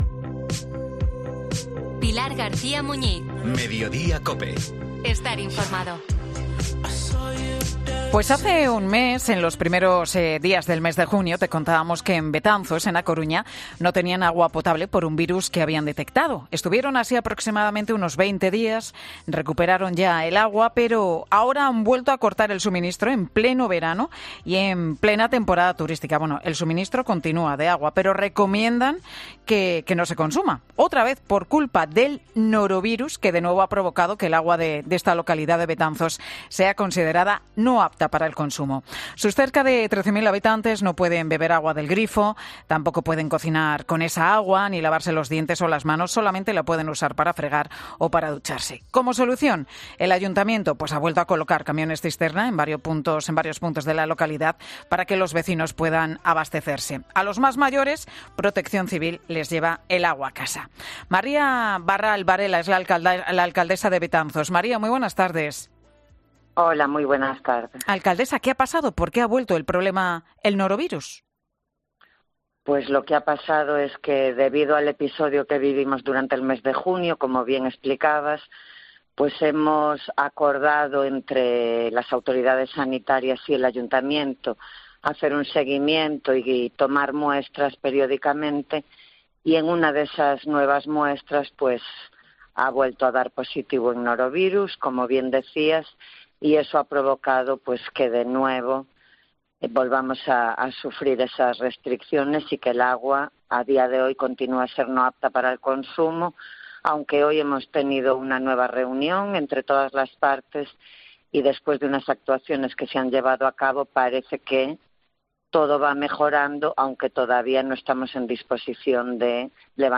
La alcaldesa de Betanzos habla en Mediodía COPE sobre las restricciones de agua